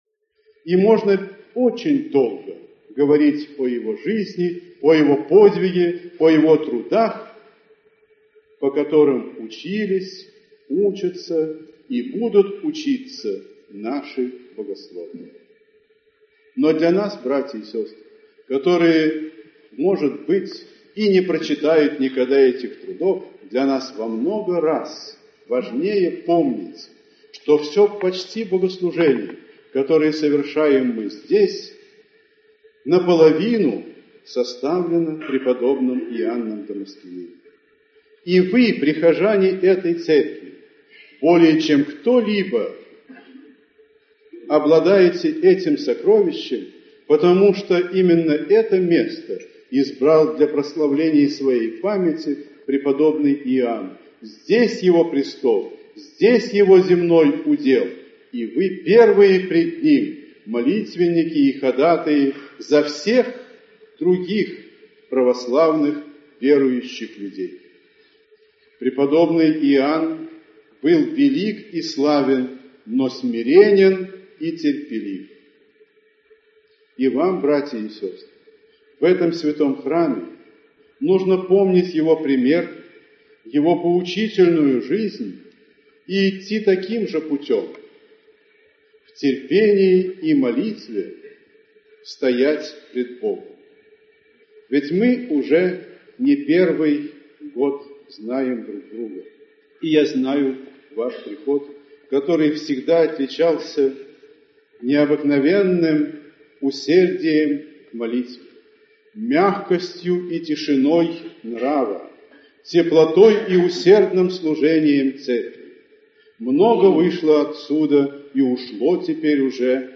Аудиокнига Преданный служитель церкви (проповеди, слова, беседы Митрополита Питирима (Нечаева)) | Библиотека аудиокниг
Aудиокнига Преданный служитель церкви (проповеди, слова, беседы Митрополита Питирима (Нечаева)) Автор Митрополит Питирим (Нечаев) Читает аудиокнигу Митрополит Питирим (Нечаев).